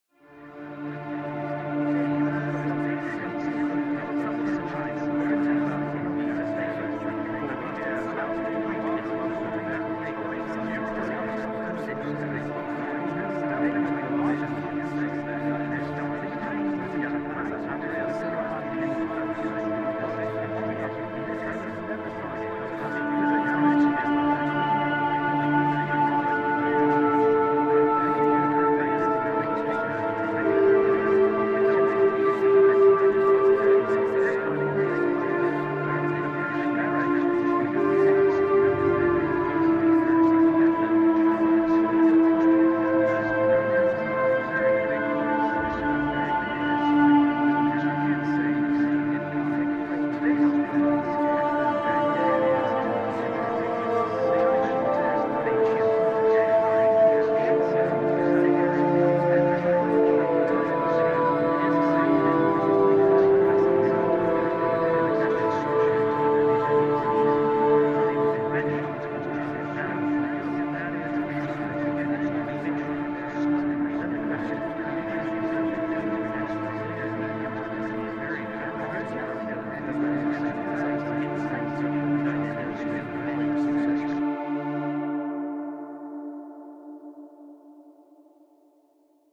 Organic soundscapes in tragedy.
Orchestral 1:29 Filmscore